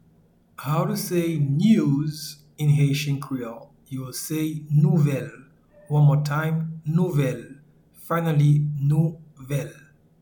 Pronunciation and Transcript:
News-in-Haitian-Creole-Nouvel-.mp3